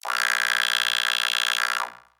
robotscream_8.ogg